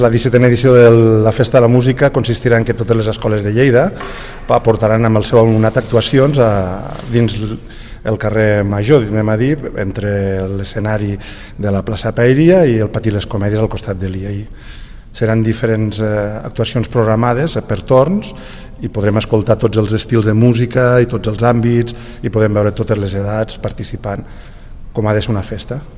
Tall de veu de la regidora Pilar Bosch sobre la presentació de la XVII Festa de la Música.